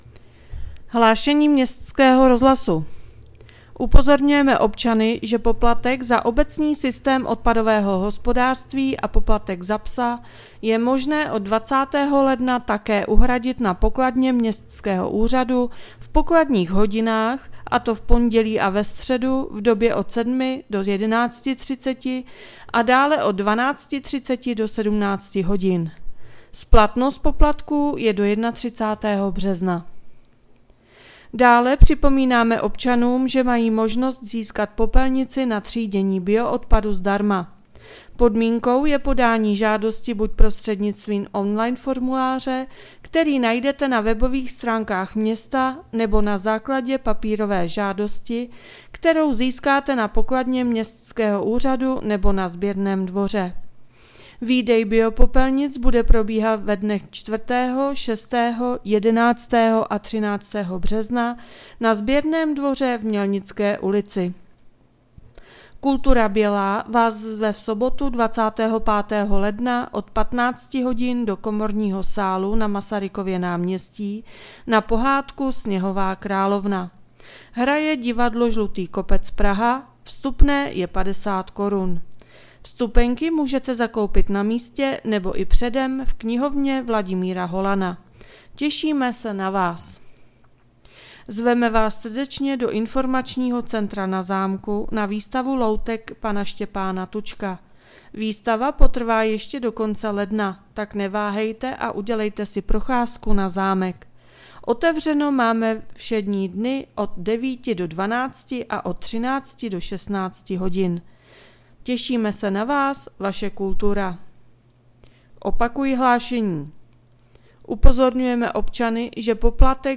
Hlášení městského rozhlasu 22.1.2025